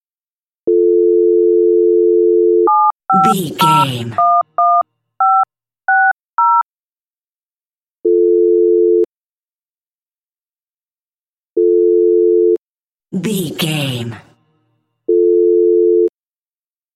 Telephone tone dial 8 numbers
Sound Effects
phone